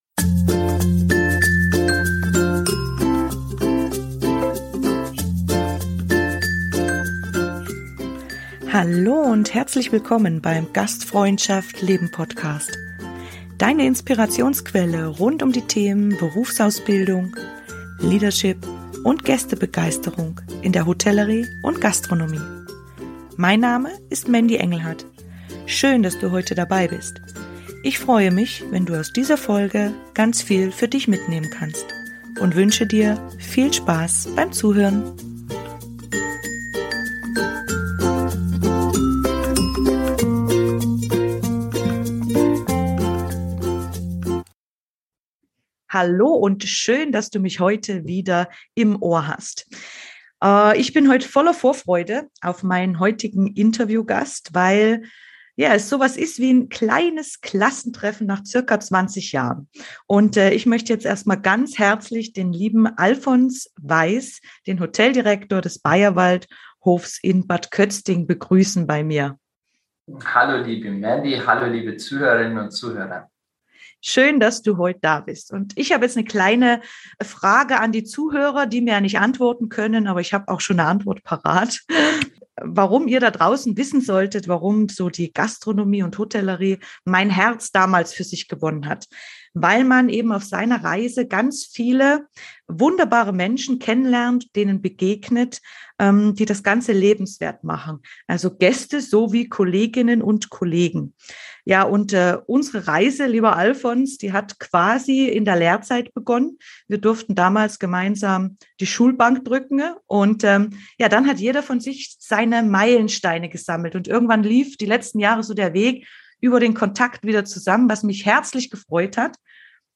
Ein Real Talk der besonderen Art wartet auf dich.